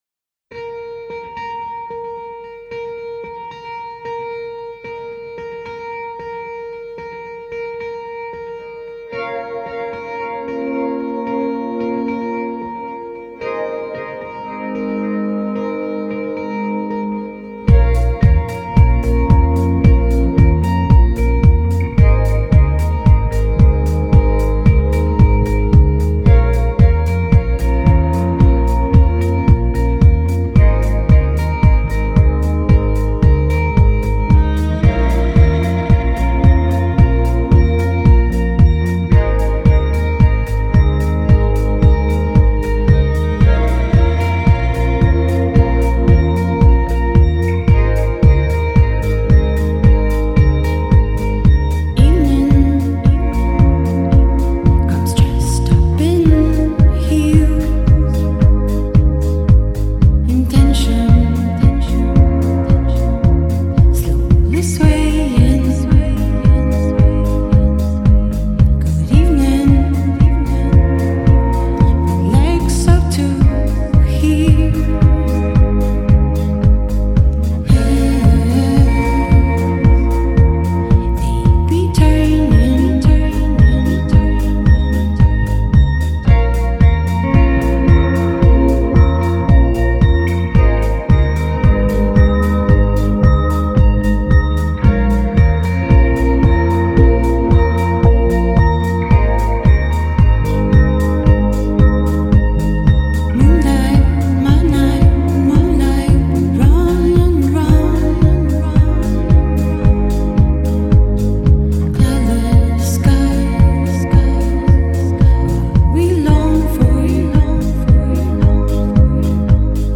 Lead and backing vocals, keyboards
Drums, percussion
Organ, keyboards, glockenspiel
Electric guitar
Keyboards, flute, sax, backing vocals